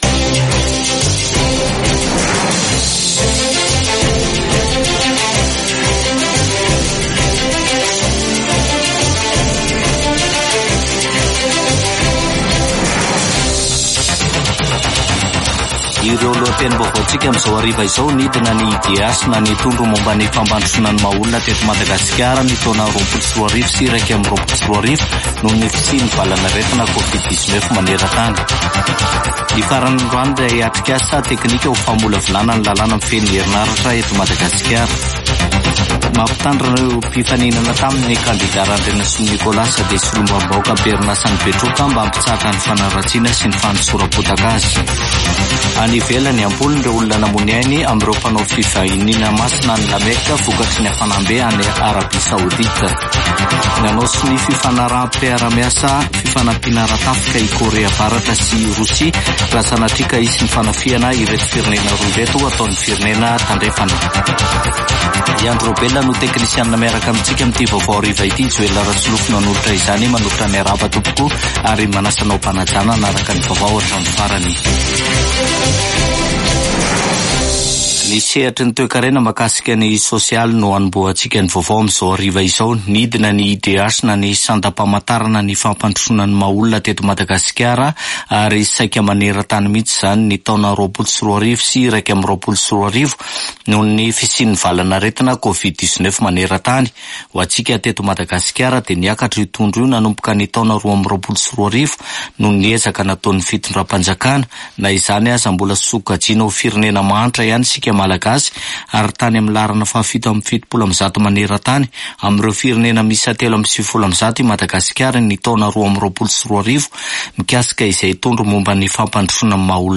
[Vaovao hariva] Alarobia 19 jona 2024